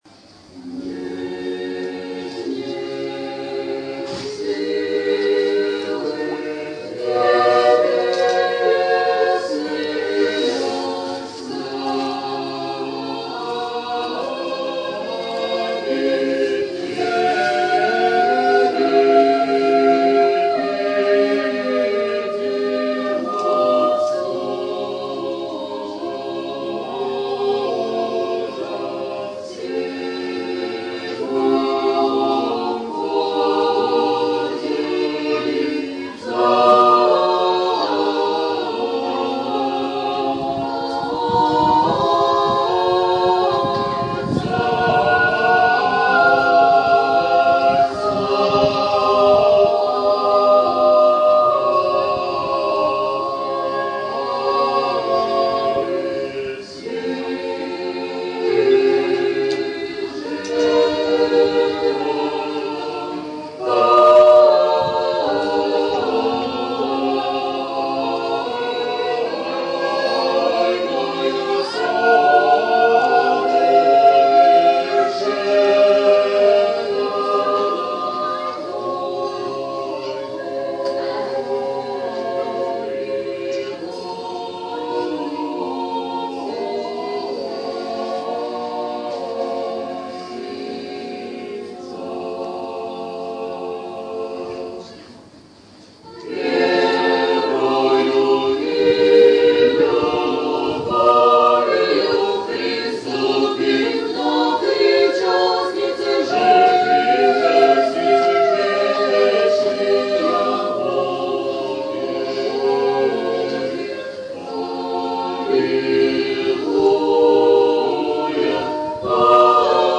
Запись сделана в паломническом комплексе при храме святого праведного воина Феодора Ушакова п.Новофедоровка